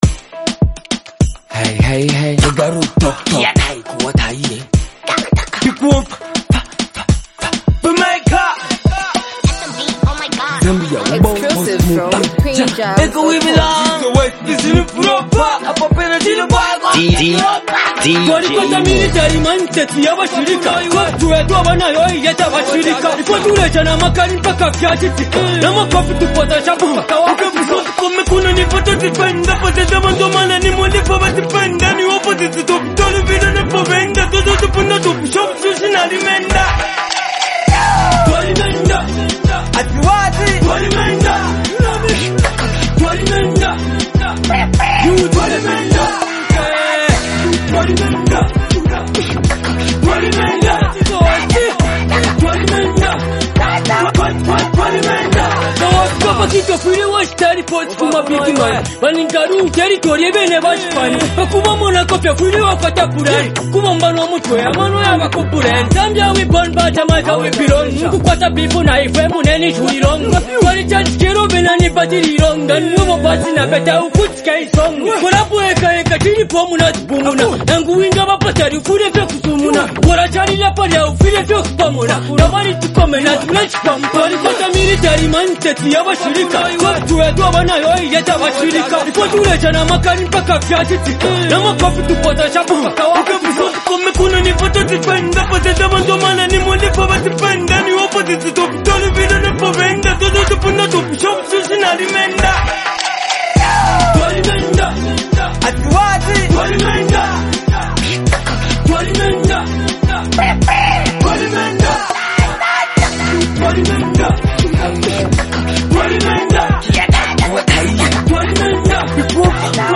a reflective and motivational song